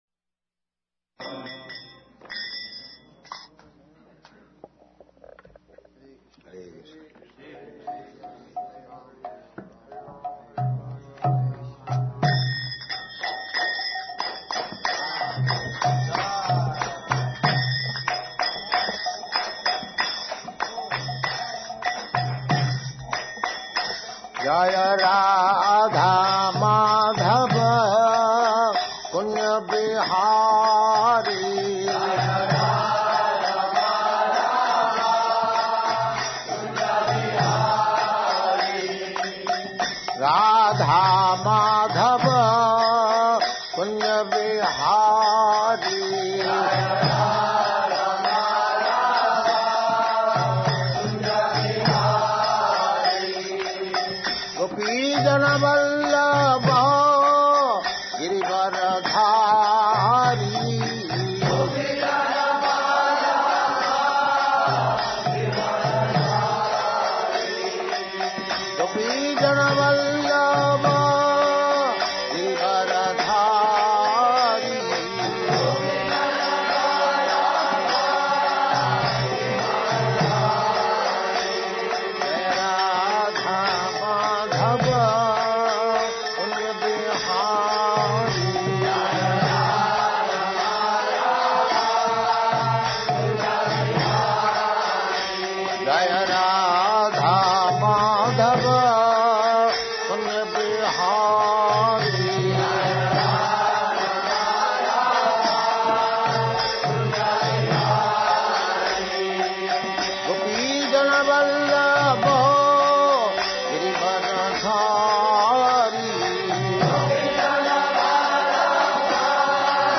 Srila Prabhupada Lecture on Srimad Bhagavatam 1.3.13, September 18, 1972, Los Angeles